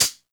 HIHAT_OUTLAWZ.wav